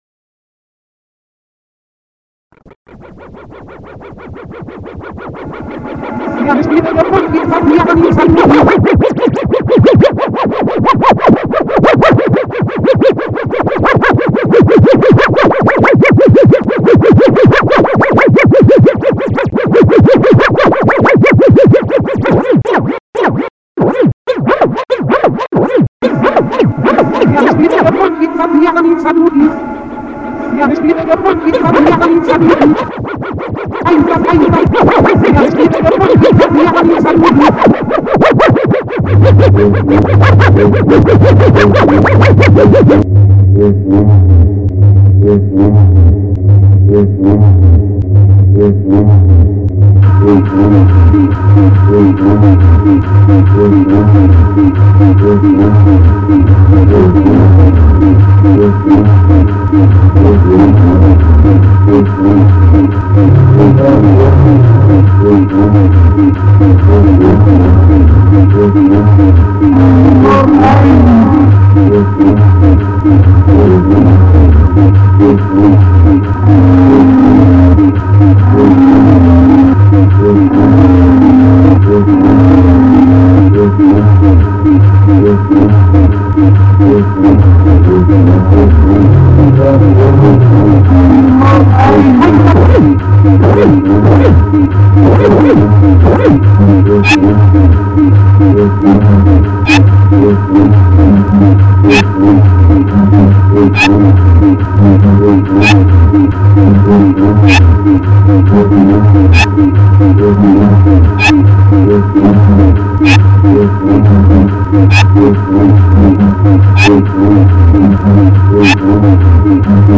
Spieldauer: 4:16 min | Unmastered | MP3